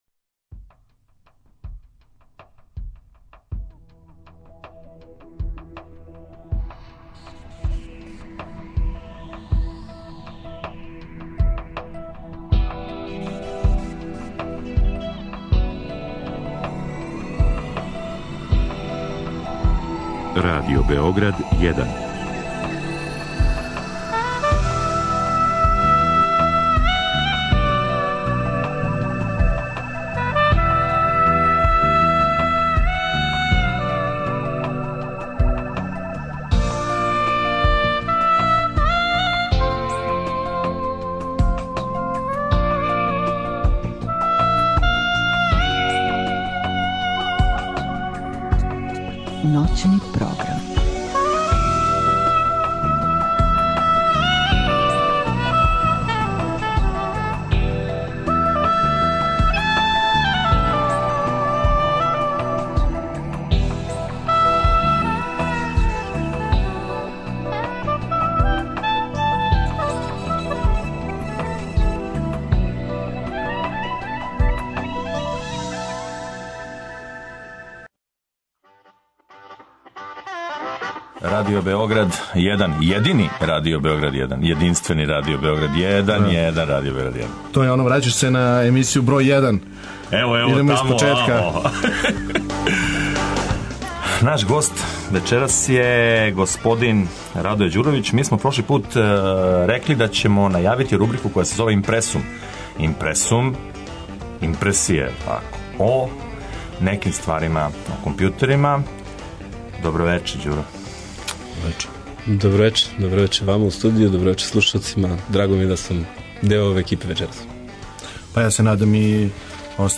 Deo ovog teksta objavljen je u IMPRESSUM-u emisije "Druge strane računara", Radio Beograd 1 [19.10.2013.godine]